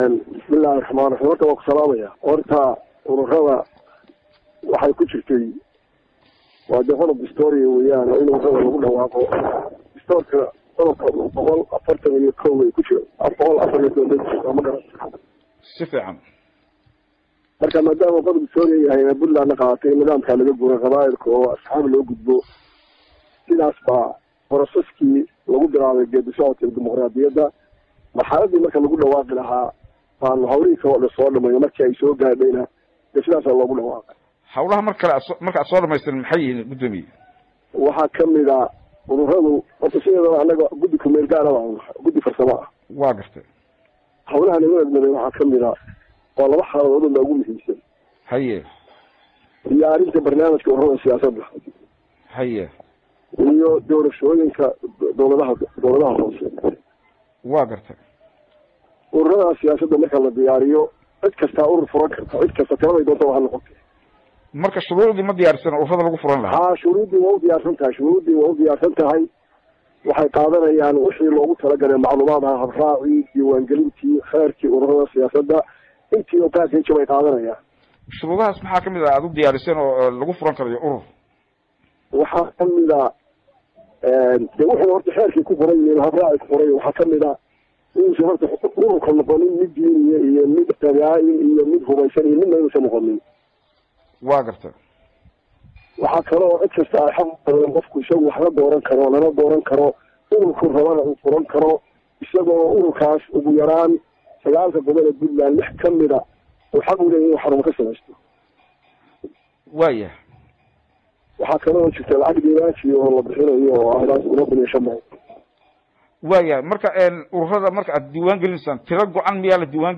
1 Abril 2017 (Puntlandes) Guddiga Doorashooyinka Puntland ayaa 25kii Marso ku dhawaaqay inay bilaabatay furashada ururada siyaasadeed ee Puntland hadaba gudoomiye kuxigeenka guddigaas ahna kusimaha gudoomiyaha guddiga Maxamuud Soofe ayaa waraysi uu siiyey radio Daljir ku faahfaahiyey Barnaamijkaas ka bilowday Puntland. Dhagayso waraysiga